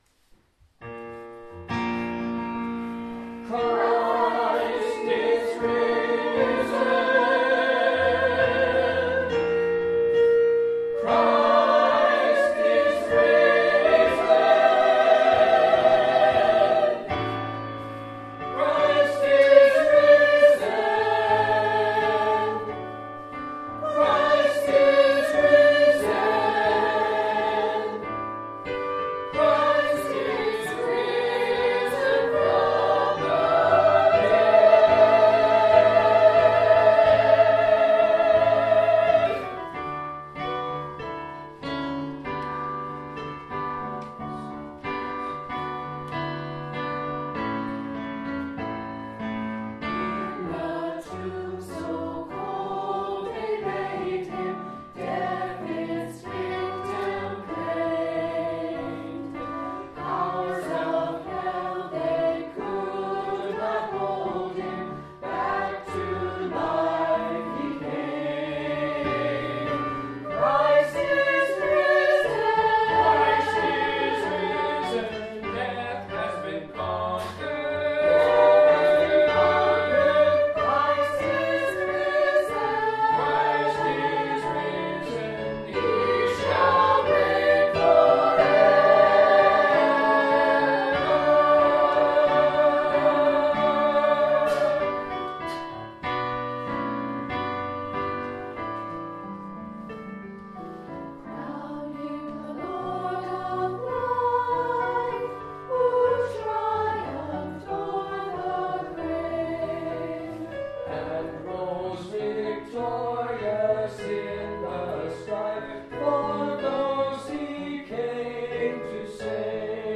BUC Worship Service, Easter Sunday – April 5, 2026
Anthem CHRIST IS RISEN
BUC-Choir_Apr5_2026_Christ-is-risen.mp3